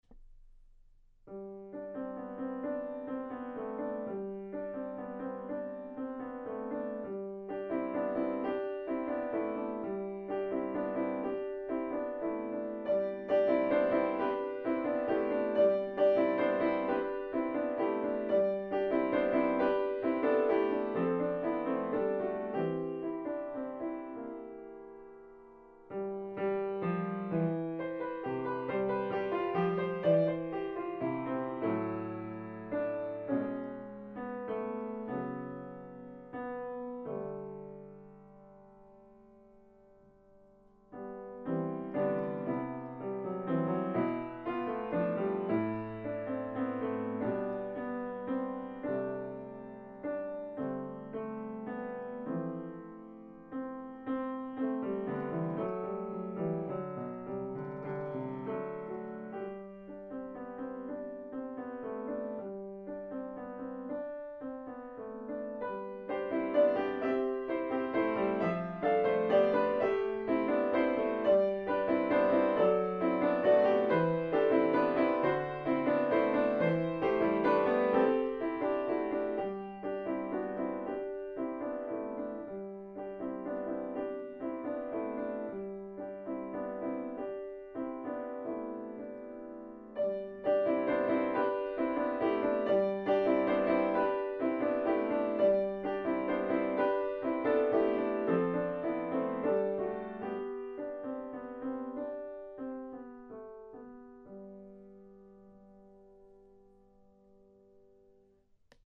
A tune that dances along; a title that implies something good.